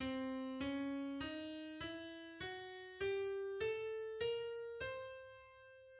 The two octatonic scales on C